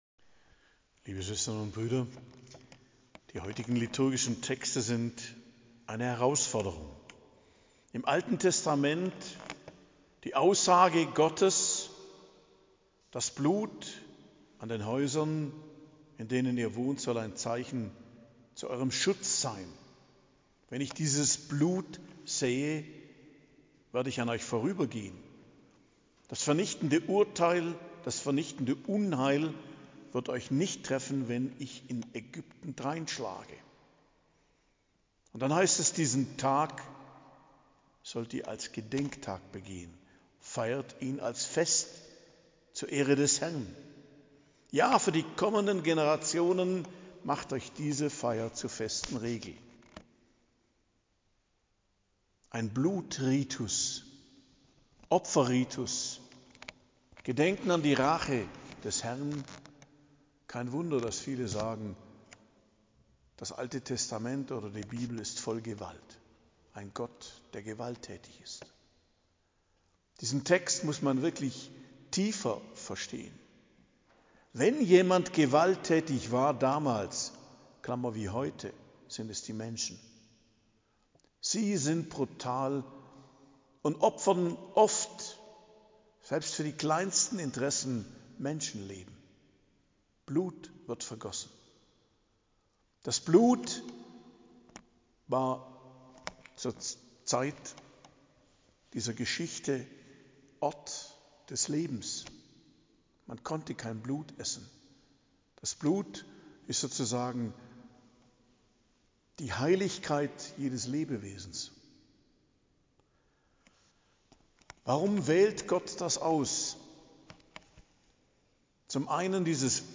Predigt am Freitag der 15. Woche i.J., 21.07.2023